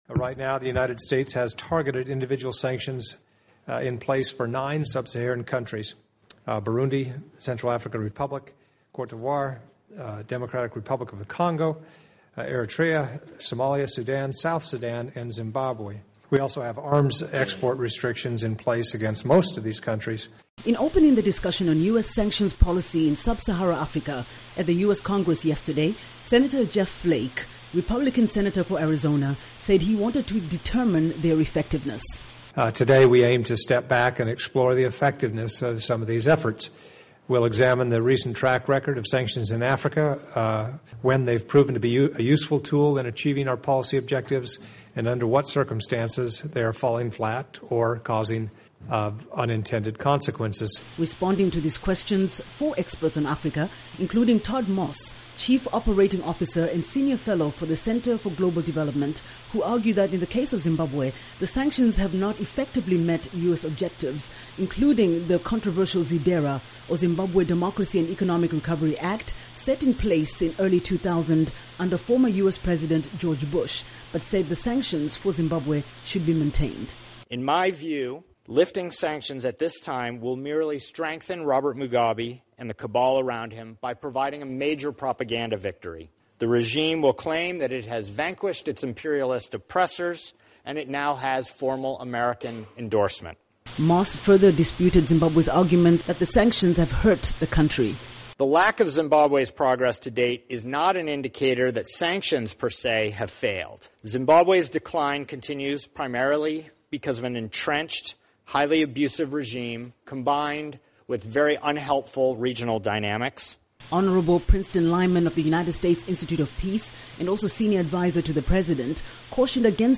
Zimbabwe is one of nine countries in sub-Saharan Africa, currently under targeted United States sanctions, and a hearing in Washington on Wednesday, sought to explore their effectiveness or necessity.
Report on Targeted Sanctions